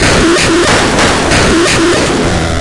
救护车专用电路弯曲友好音乐 " D R550 7
标签： 弯曲 弯曲的 电路 deathcore DR550 毛刺 杀人 玩弄
声道立体声